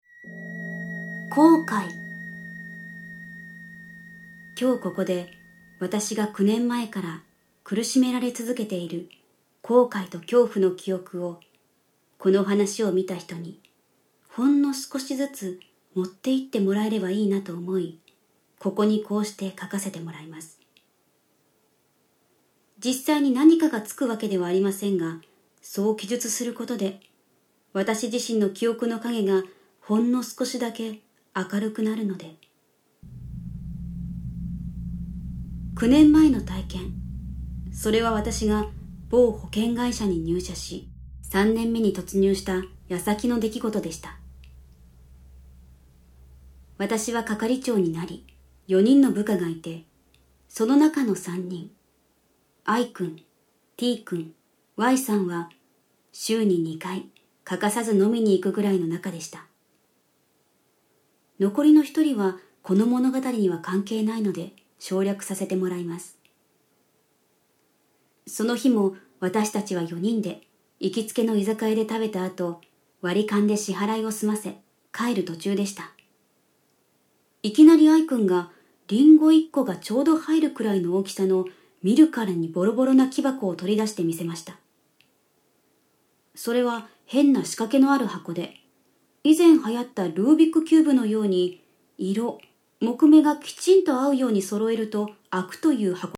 [オーディオブック] ネットであった本当に怖い実話 3D Vol.3-5 〜Q〜 (下)
SEにもこだわり、最先端技術を駆使し、擬似的に3D音響空間を再現、格別の臨場感を体感出来ます！